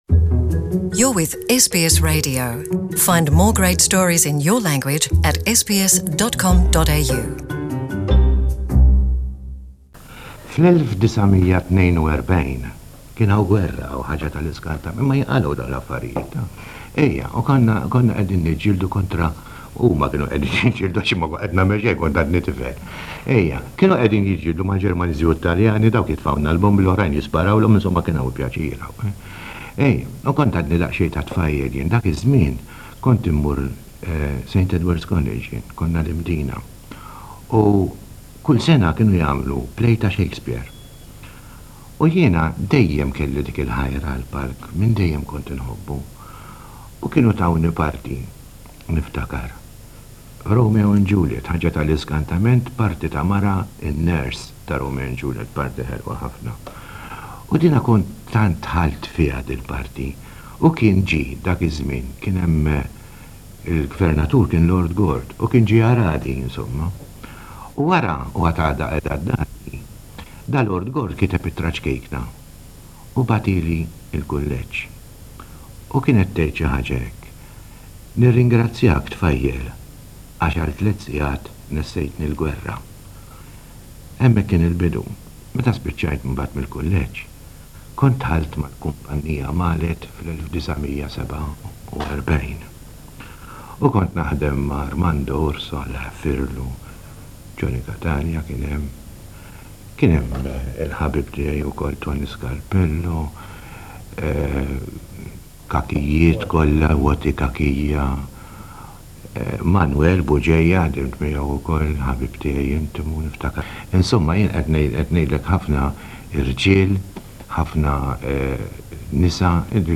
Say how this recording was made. In this interview with SBS Radio in 1980, he talks about how he started his acting career.